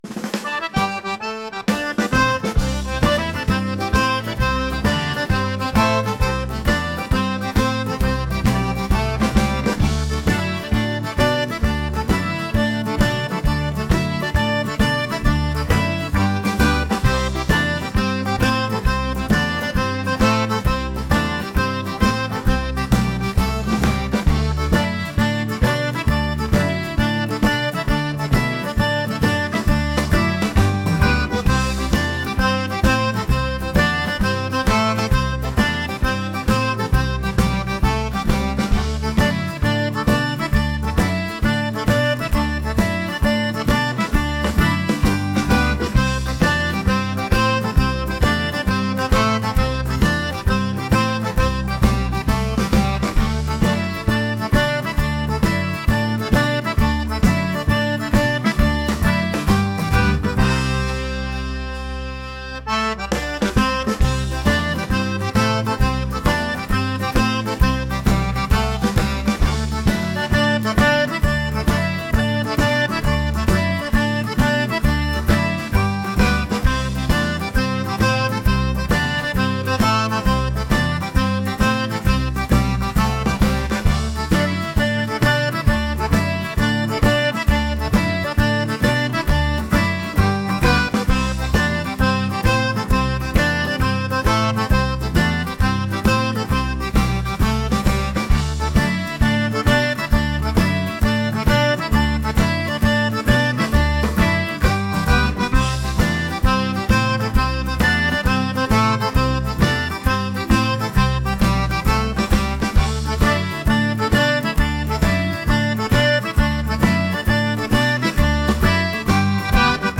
lively | energetic